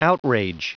Prononciation du mot outrage en anglais (fichier audio)
Prononciation du mot : outrage